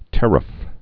(tĕrəf)